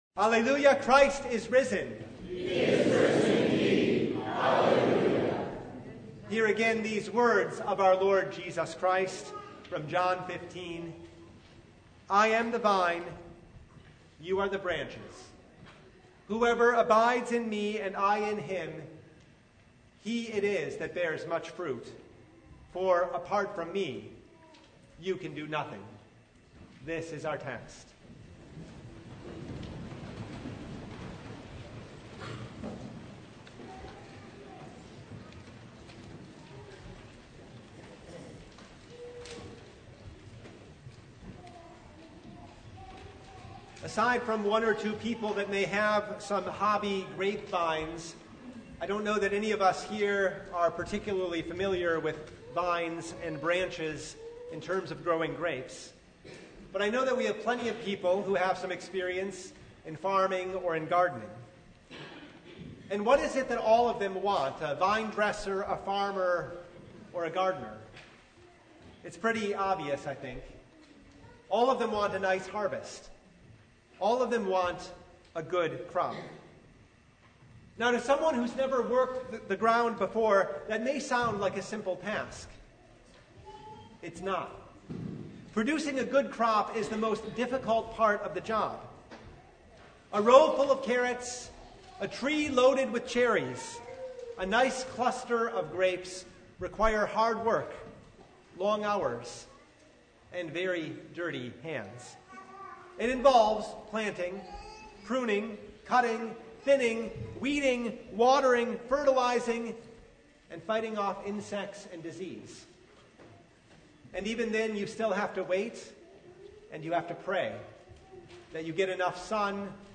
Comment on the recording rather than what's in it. John 15:1-8 Service Type: Sunday Jesus is the vine.